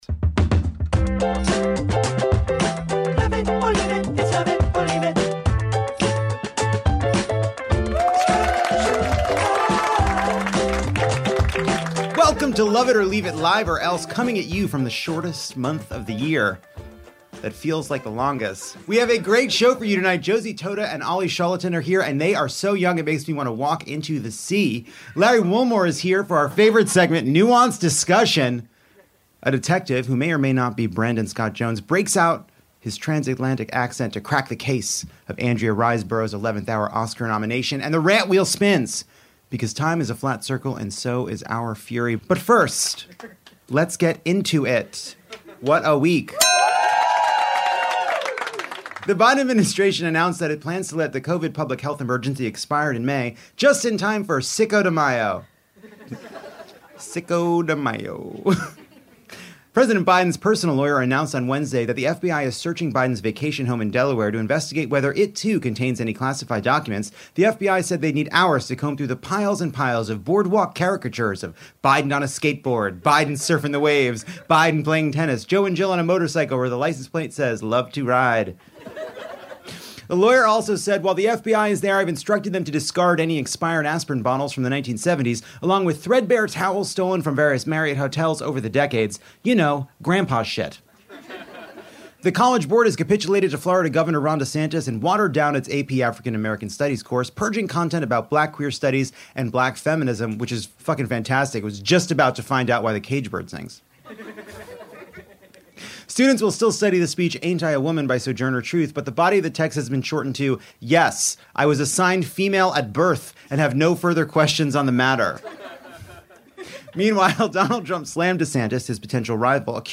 Live from the SiriusXM studio, Lovett Or Leave It pits DeSantis vs Trump in a battle for the... well, not the soul of the country.